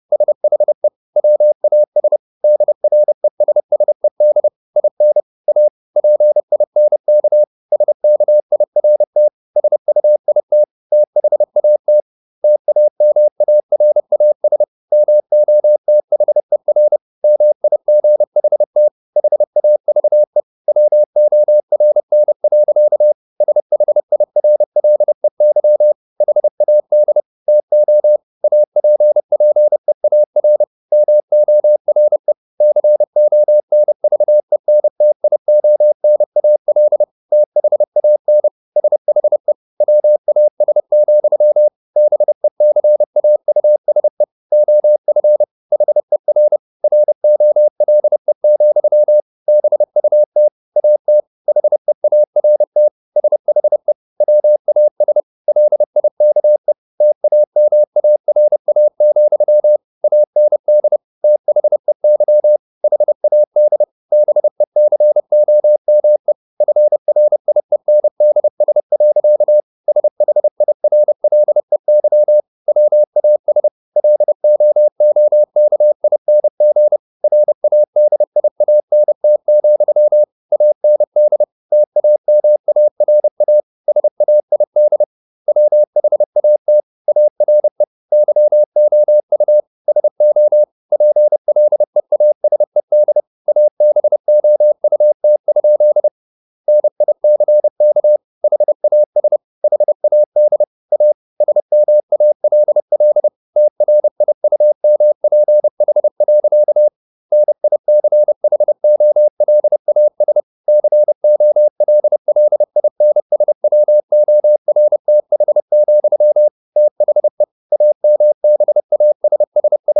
Never 30wpm | CW med Gnister